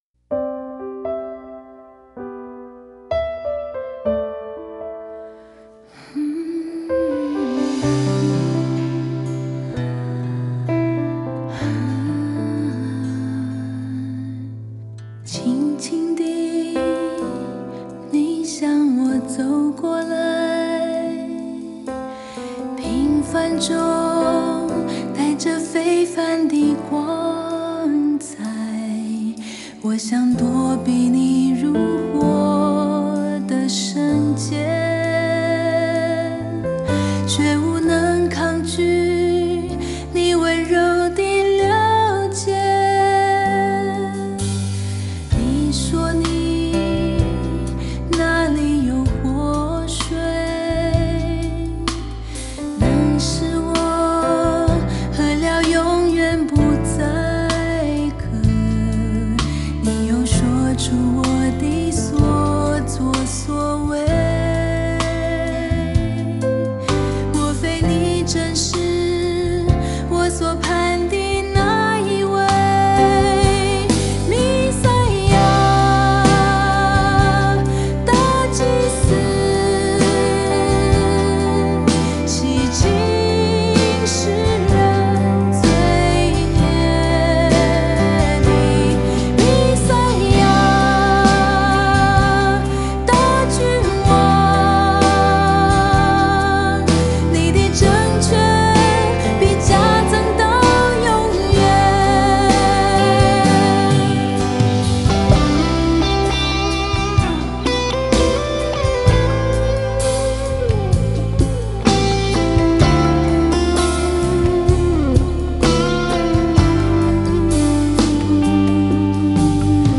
前奏 → 主歌 1 → 主歌 2 → 副歌上 → 間奏 → 主歌 2 → 副歌 → 副歌 → 結尾